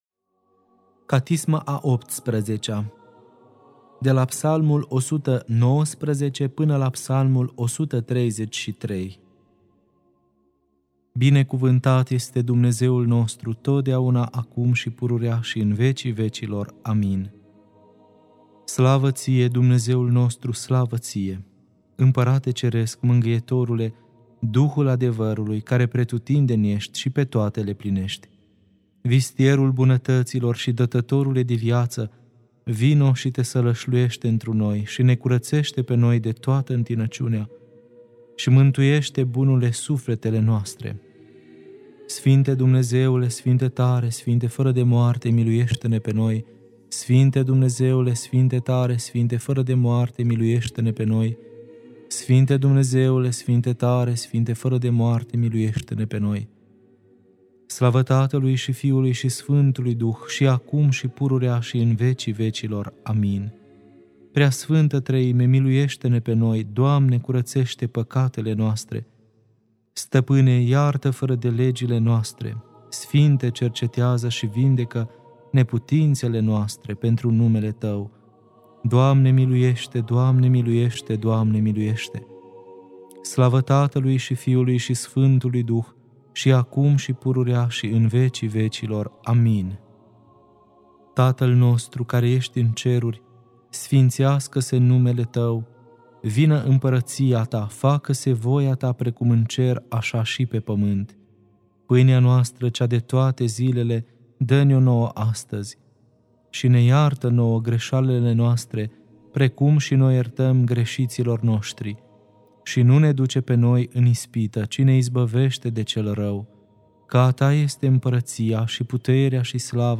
Catisma a XVIII-a (Psalmii 119-133) Lectura